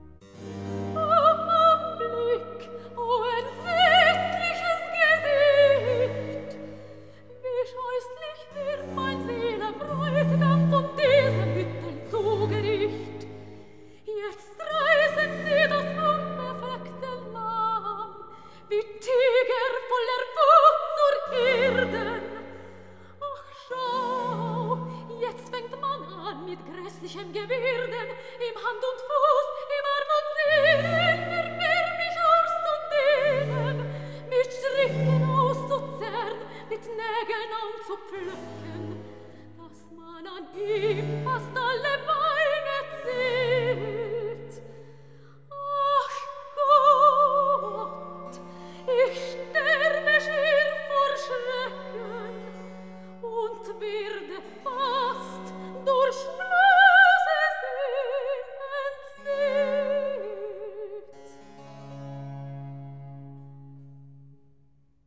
Recitativo